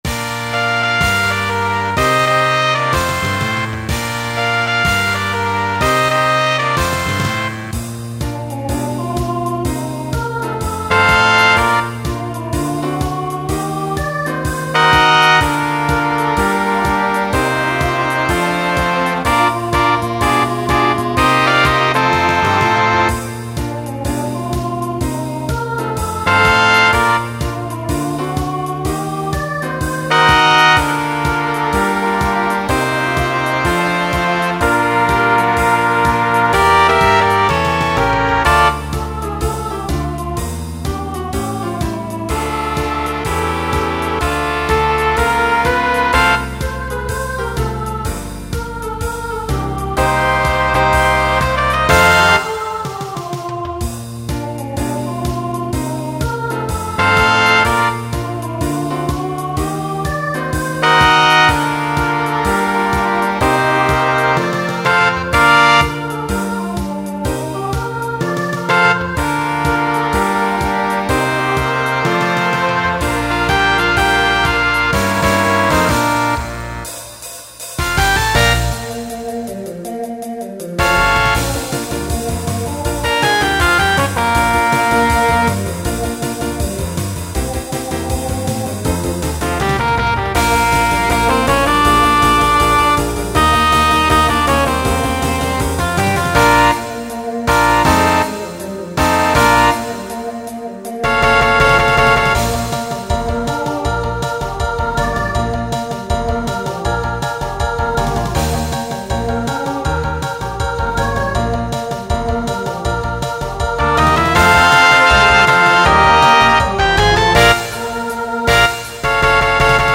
(Solo)
(TTB)
(SSA)